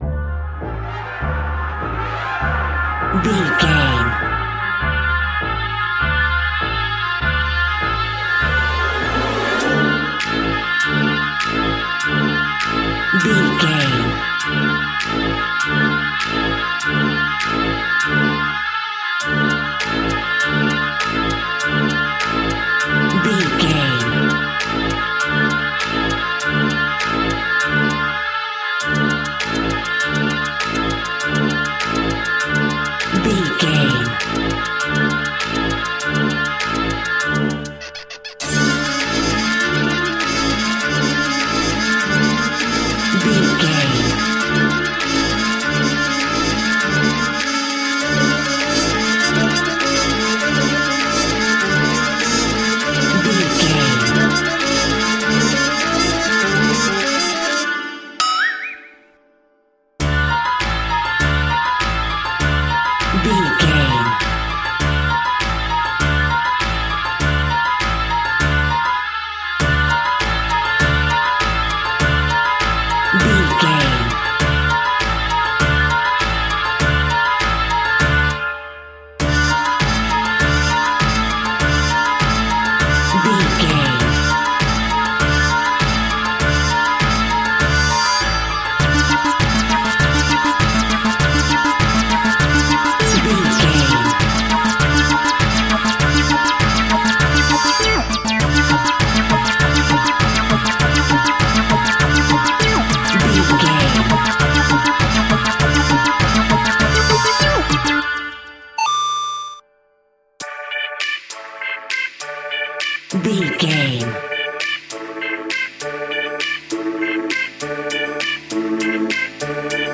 Aeolian/Minor
ominous
eerie
brass
drums
synthesiser
piano
electric organ
creepy
horror music